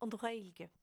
They are essentially the same word in different accents.
Rather than a "w" with the lips, try to make a gentle "u" in the throat.